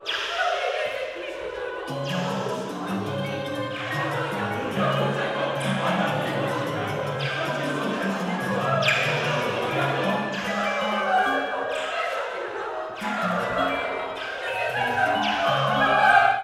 [from non-commercial, live recordings]